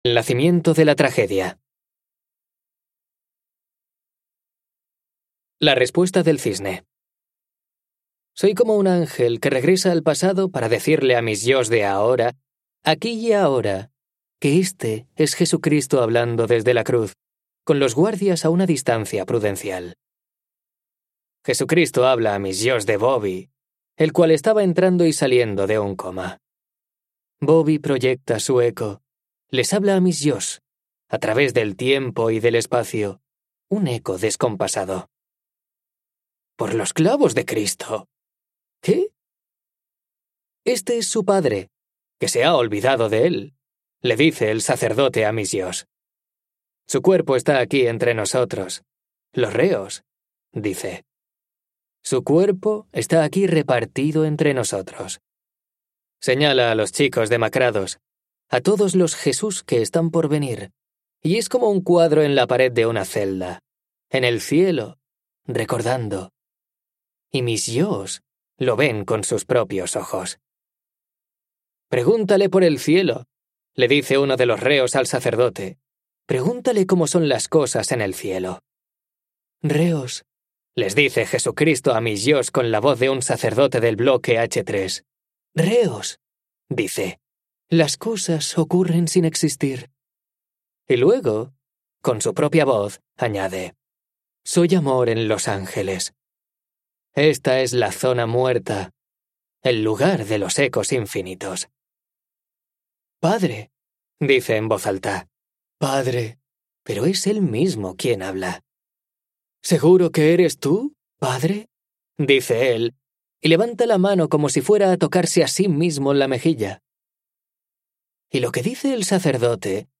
TIPO: Audiolibro CLIENTE: Storytel ESTUDIO: Eclair Barcelona